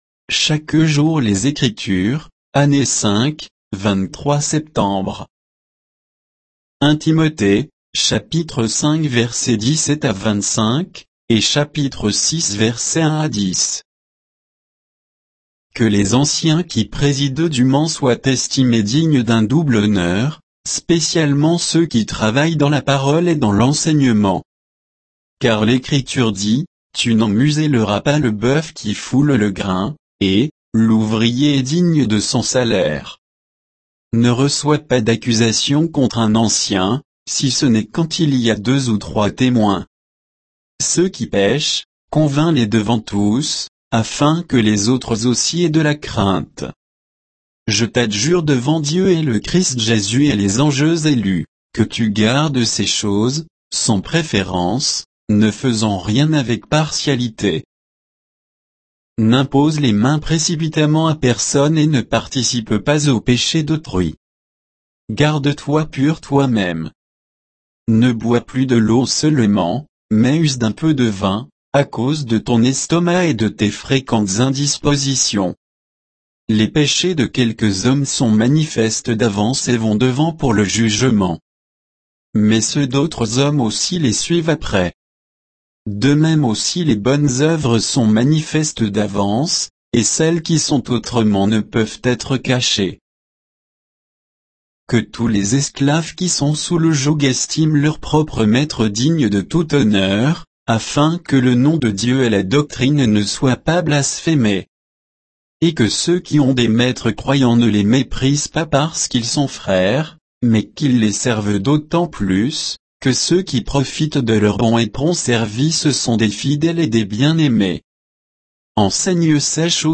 Méditation quoditienne de Chaque jour les Écritures sur 1 Timothée 5, 17 à 6, 10